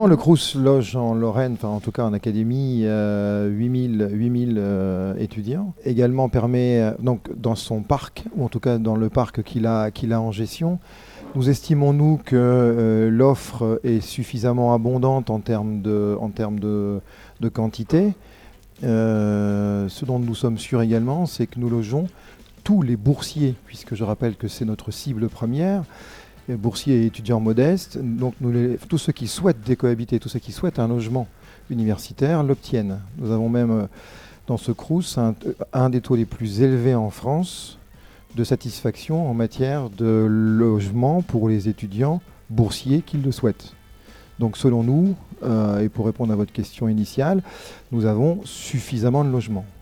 Ce jeudi 1er février, différents acteurs du logements étudiants se sont retrouvés autour d’une table pour parler de plusieurs aspects sur les habitations.